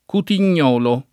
Cutignolo [ kutin’n’ 0 lo ]